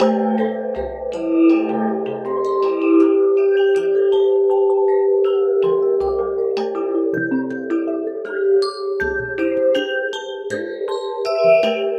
Gamalan_80_C.wav